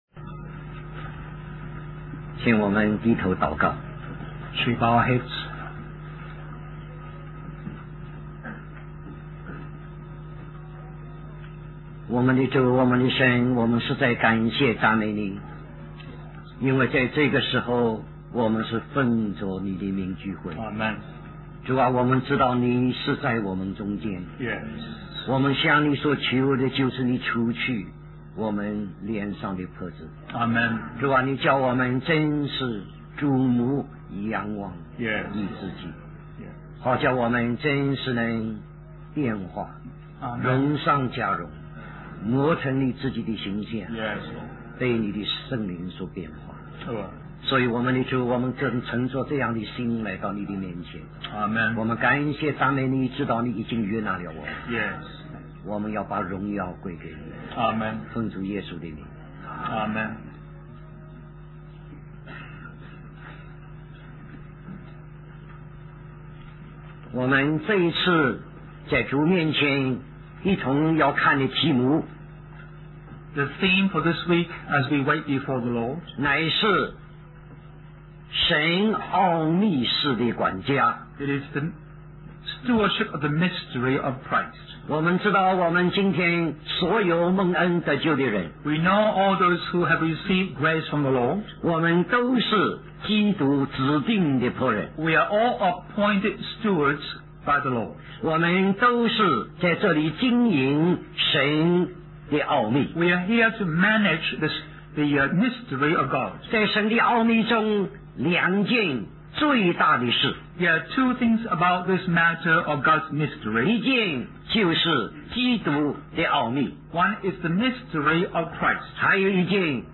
Special Conference For Service, Singapore